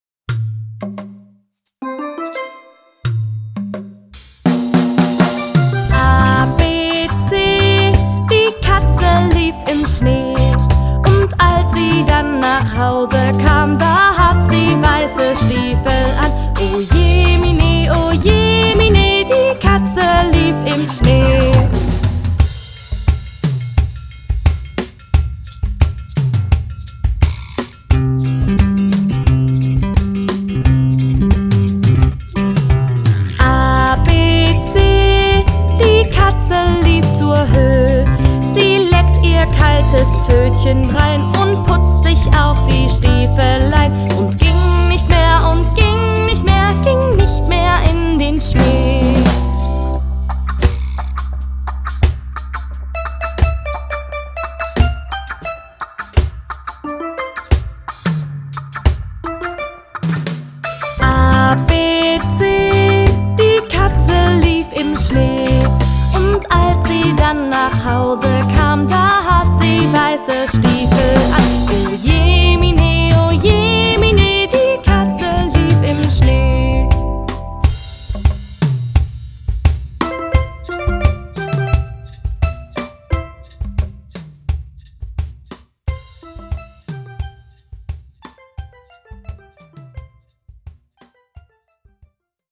Lernlieder